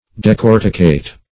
Decorticate \De*cor"ti*cate\, v. t. [imp. & p. p.